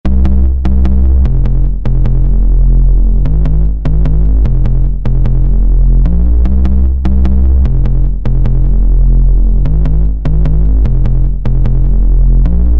#3 Beat Plugin für fetten Bass
03_phase_plant_bass.mp3